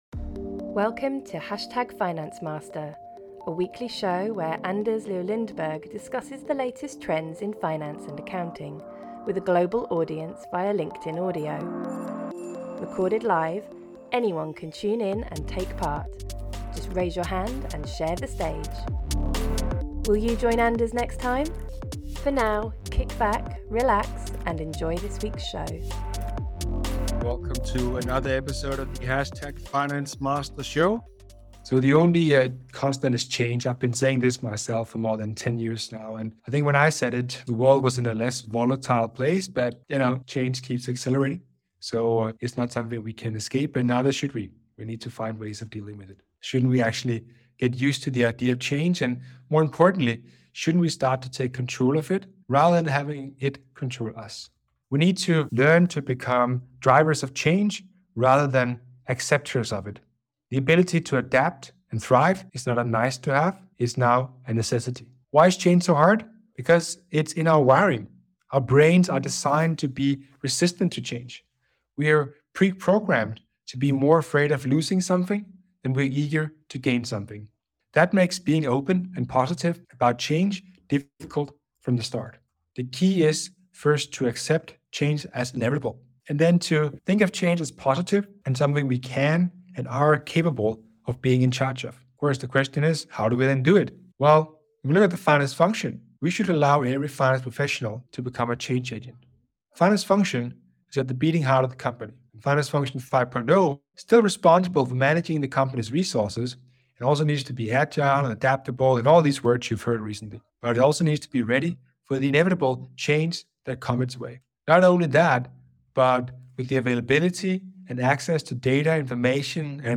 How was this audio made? *Due to the live nature of the show, unfortunately this episode encountered some audio issues *